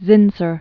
(zĭnsər), Hans 1878-1940.